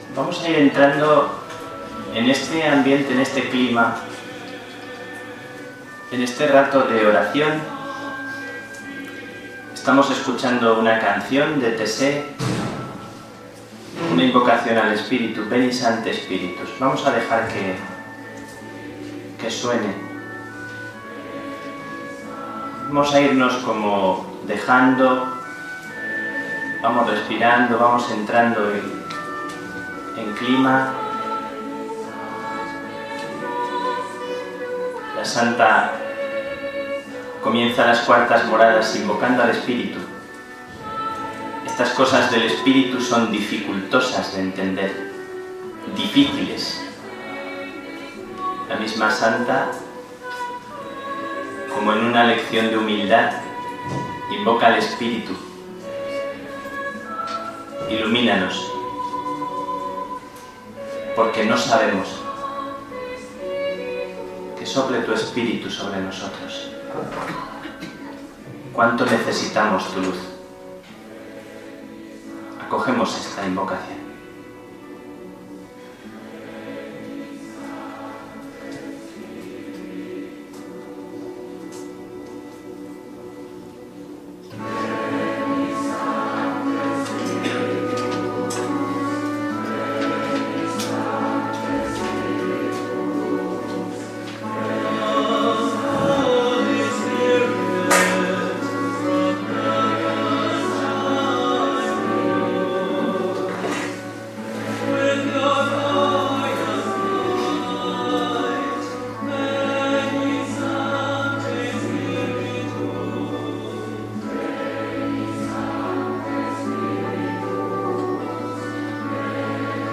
Audio Taller de Oración – Cuartas Moradas